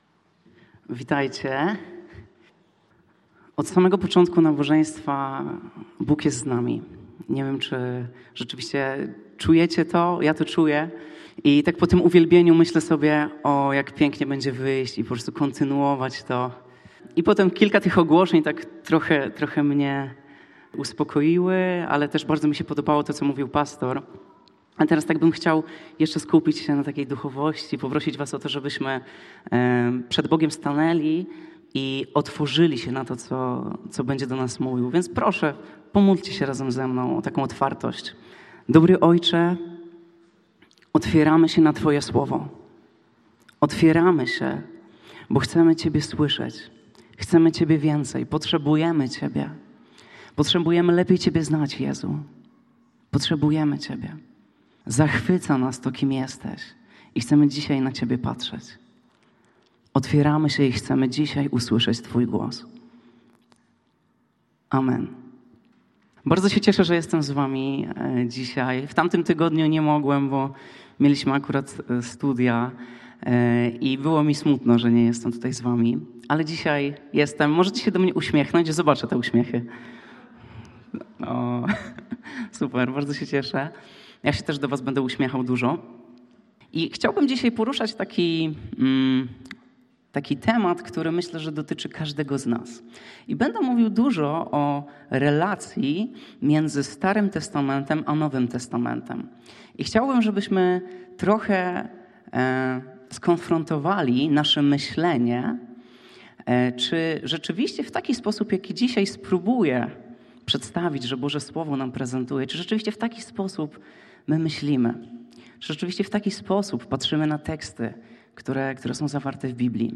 (całość nabożeństwa)